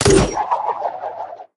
gr_launch01.ogg